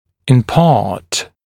[ɪn pɑːt][ин па:т]частично, отчасти